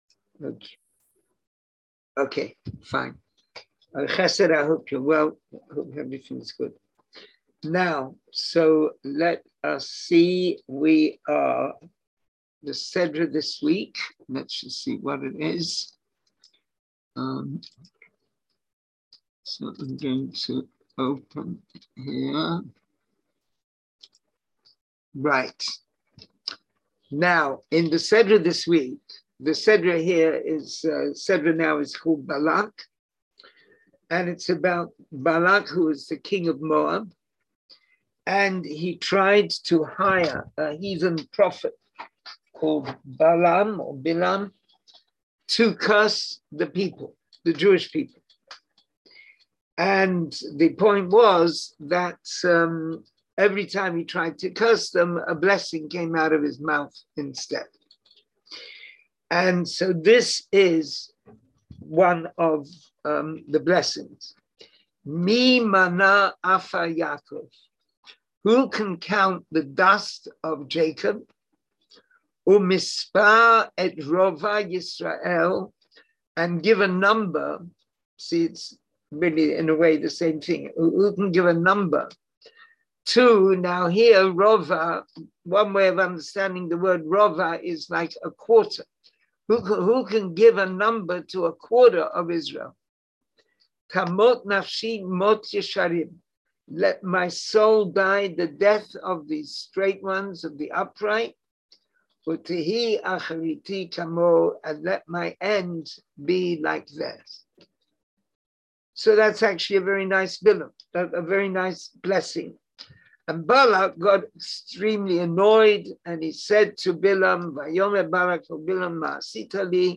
Class audio Listen to the class Watch the video Class material Hebrew Summary of Discourse English Summary of Discourse Join the class?